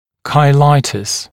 [kaɪˈlaɪtəs][кайˈлайтэс]хейлит (воспаление зуба)